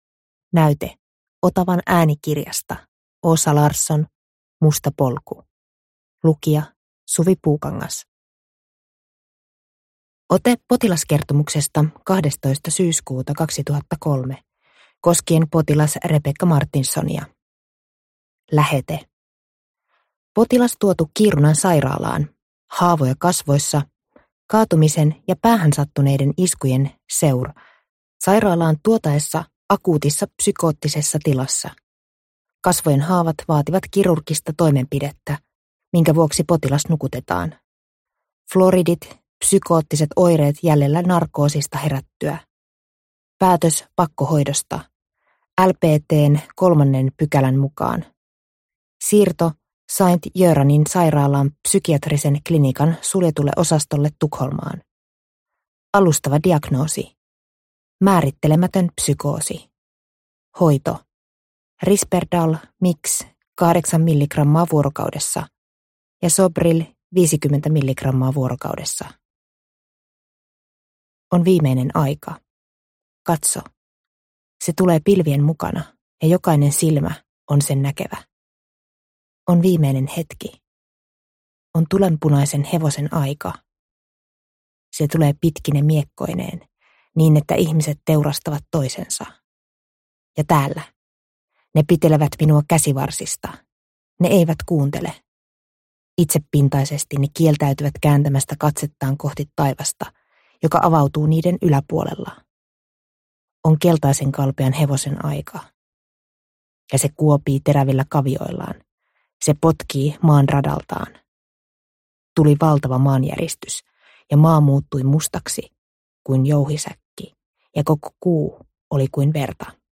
Musta polku – Ljudbok – Laddas ner